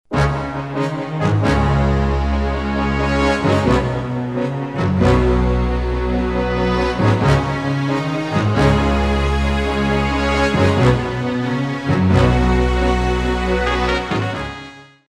Industrial Music Samples
Industrial 54a